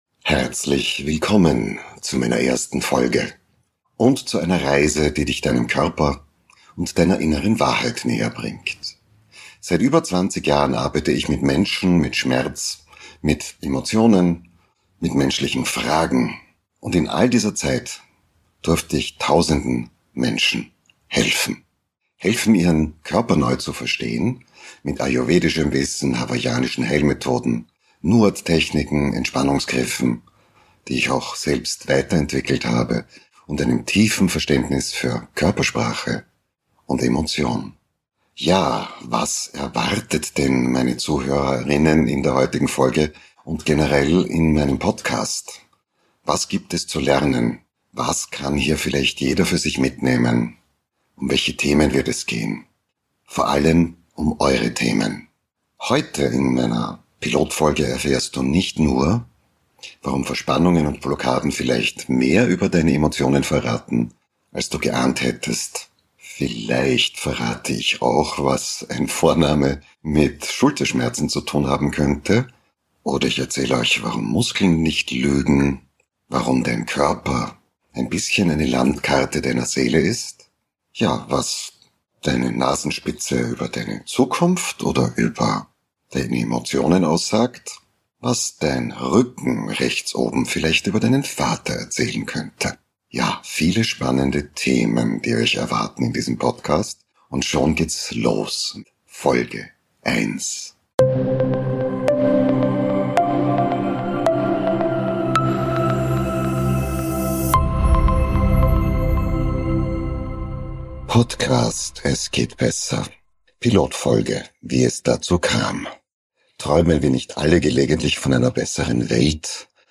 Auf einem samtigen fliegenden Stimmteppich, der dir kleine und große Wunder zeigt, in dir drin und da draußen in der Welt!